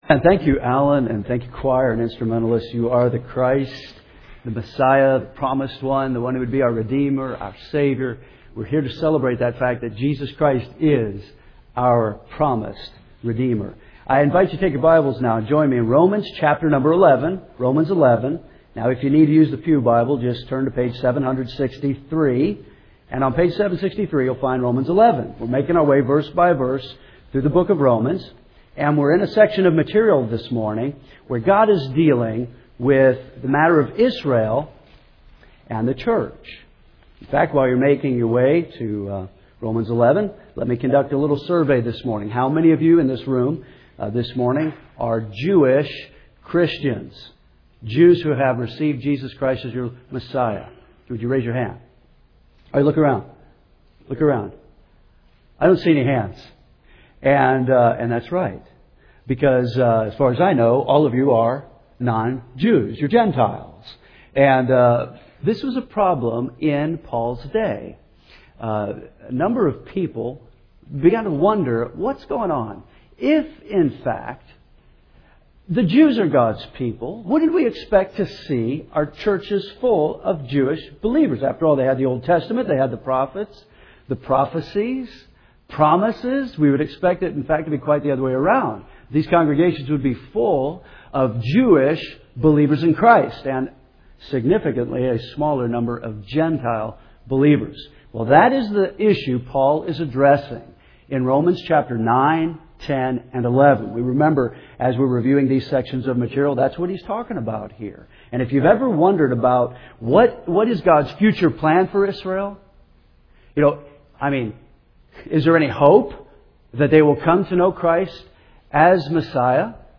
First Baptist Henderson, KY